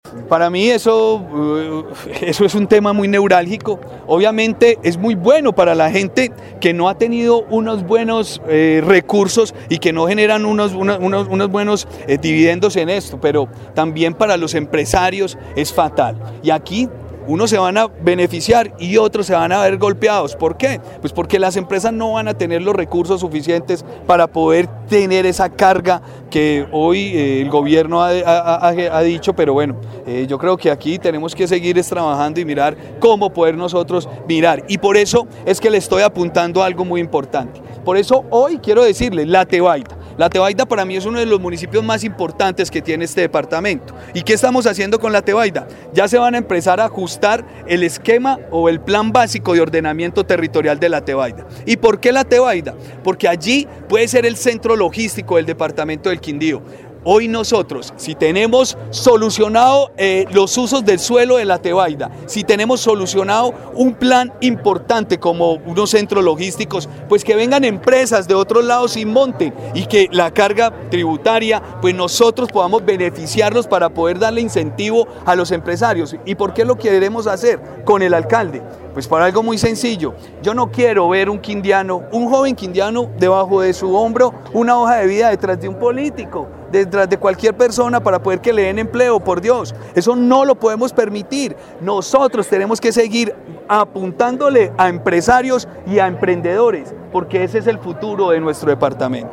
Gobernador del Quindío